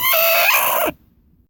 Chicken Scream - Harsh
chicken creature hen scream sound-effect vocalization sound effect free sound royalty free Voices